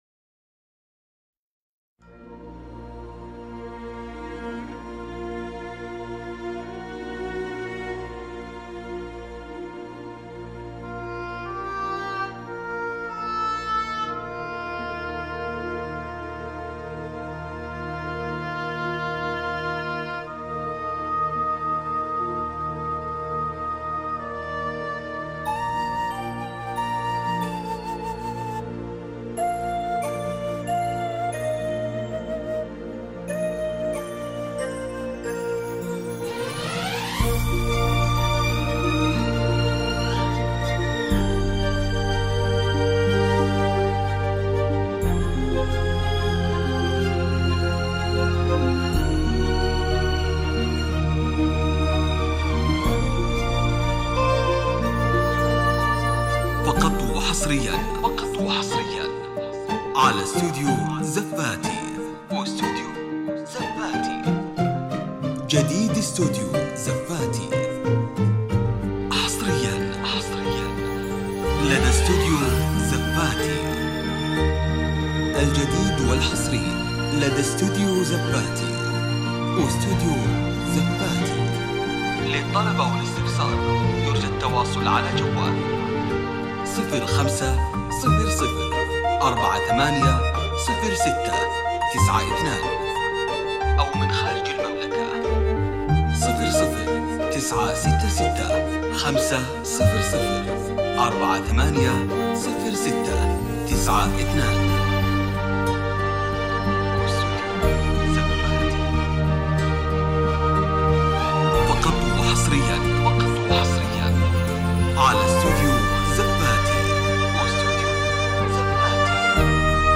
زفة عريس